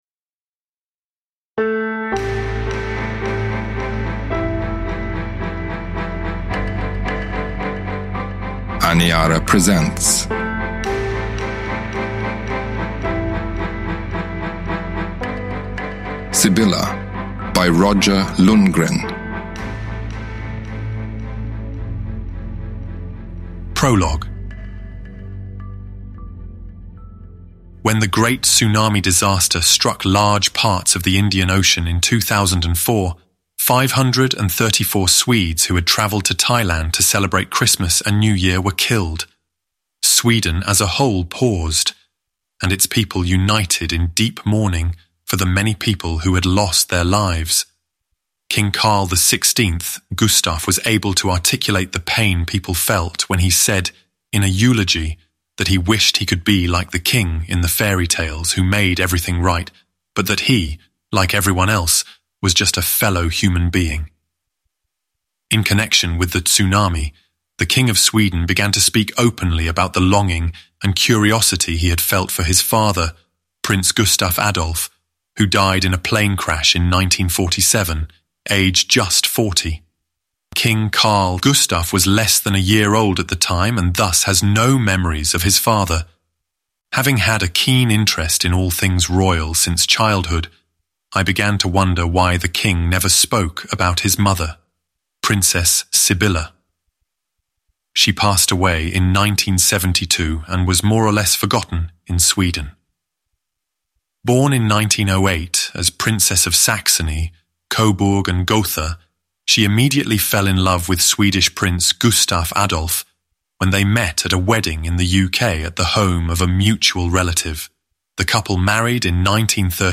Sibylla – Ljudbok